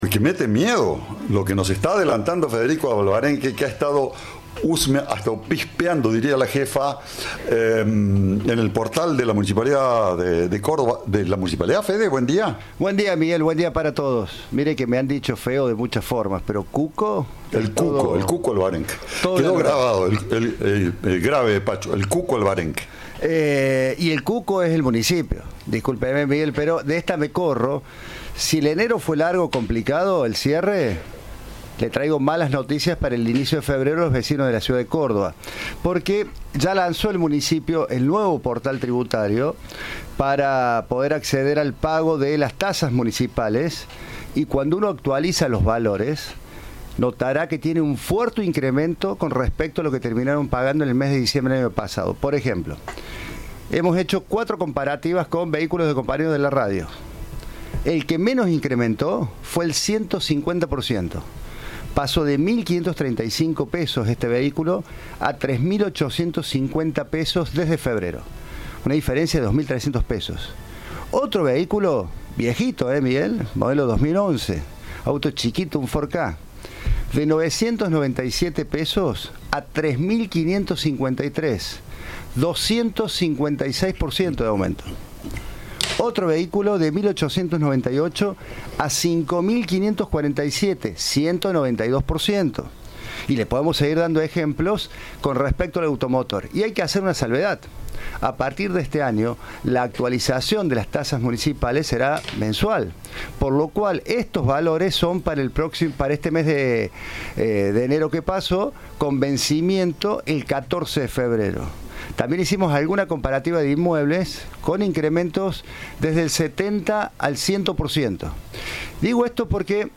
Sobre ello dialogó en Cadena 3 el secretario de Administración Pública y Capital Humano de la Municipalidad de Córdoba, Sergio Lorenzatti.